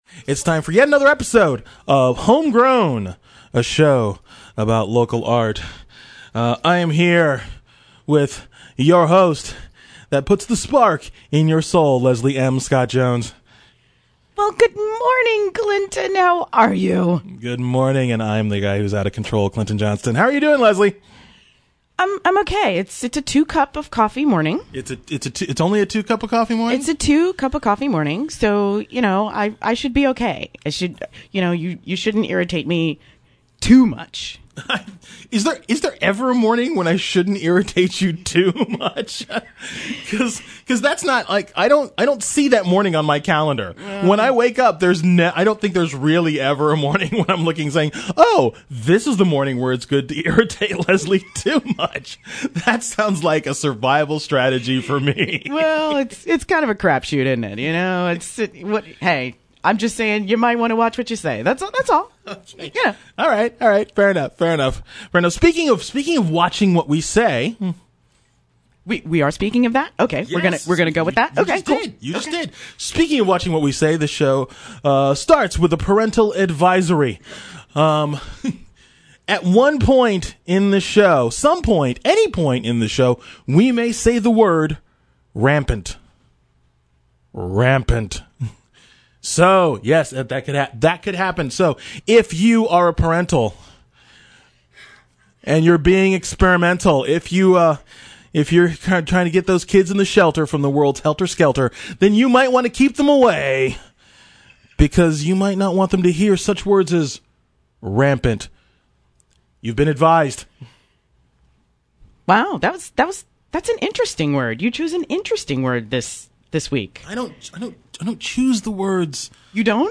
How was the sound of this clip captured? Home Grown is heard on WPVC 94.7 Sunday mornings at 10:00 a.m.